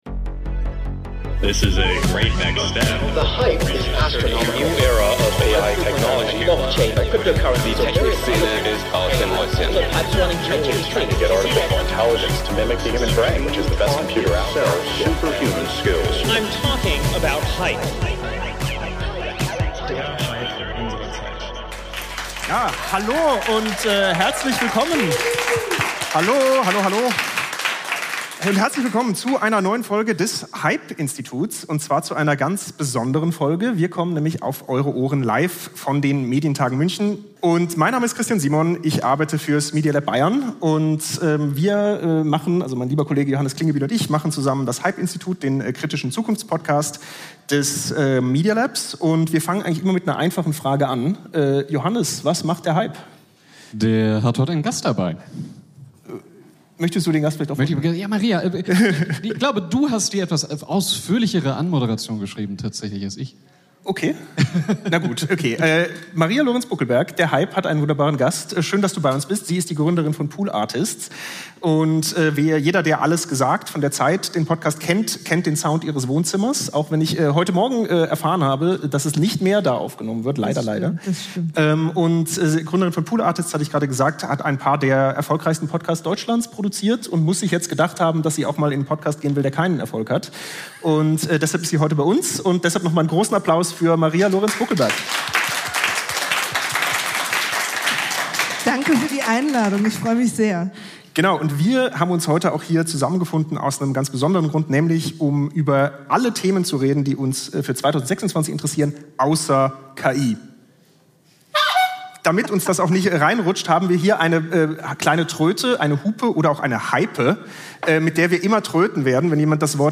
Live von den Medientagen München 2025